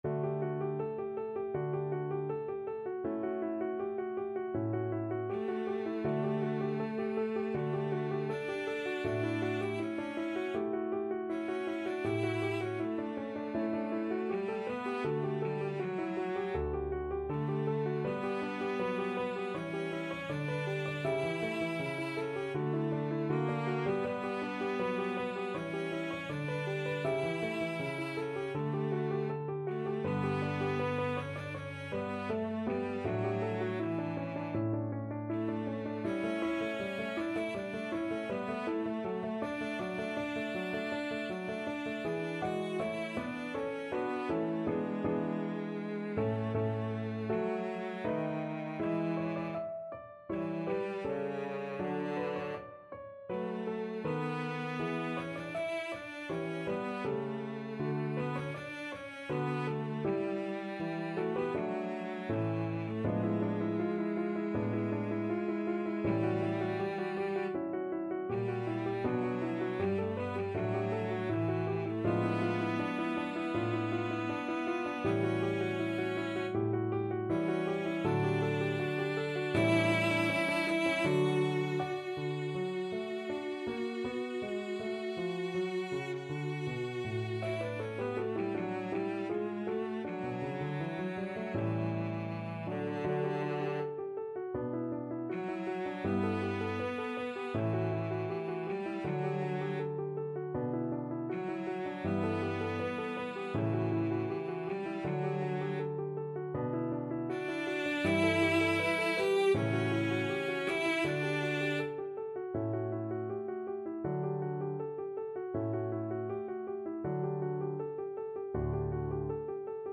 Cello 1Cello 2
Andante
Classical (View more Classical Cello Duet Music)